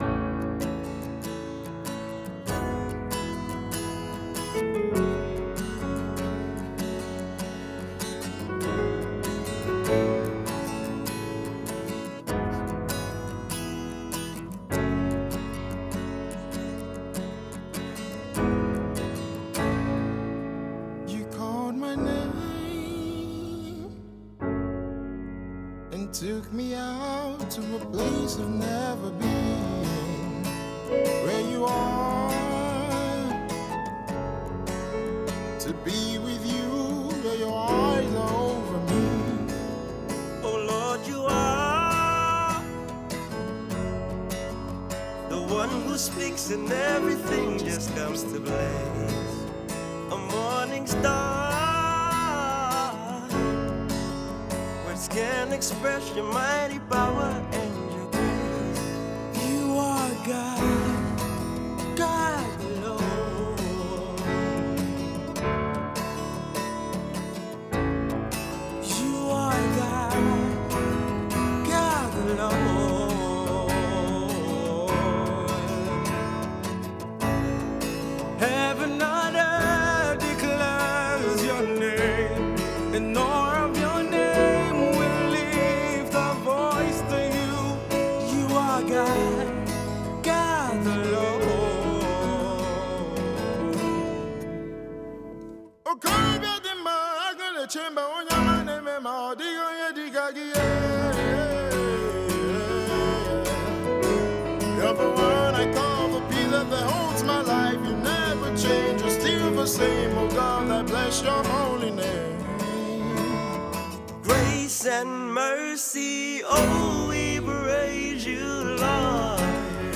Gospel group
guitar and vocals
keyboard and vocals